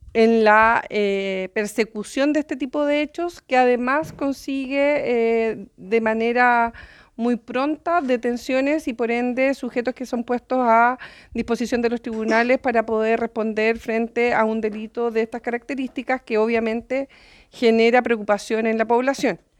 Por su parte, la seremi de Seguridad Pública del Bío Bío, Paulina Stuardo, recalcó la importancia de realizar estos procedimientos de manera rápida.